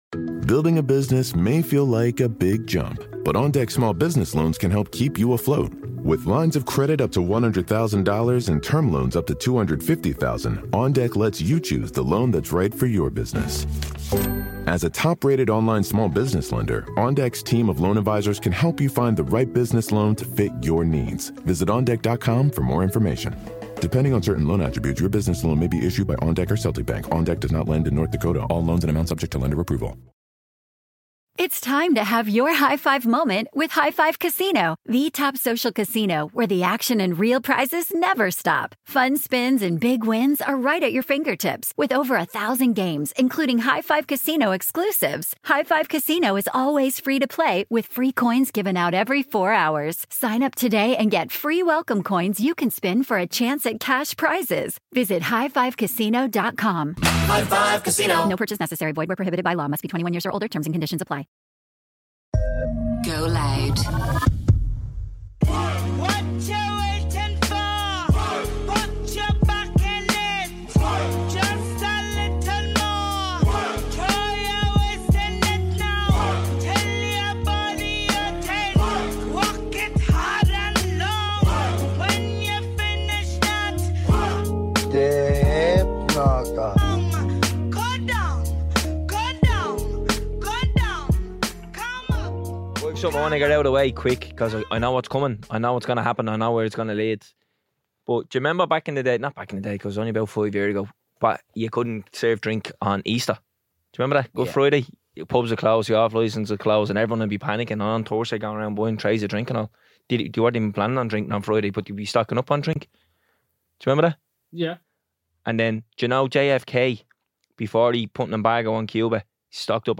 Two lads from the inner city of Dublin sitting around doing what they do best, talking bollox.